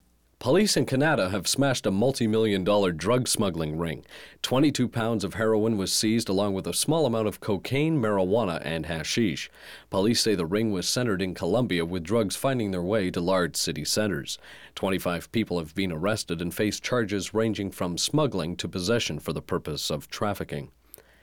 Här är ett exempel på EBU R128
Limiterad_Peak-11dB_EBU_R128.wav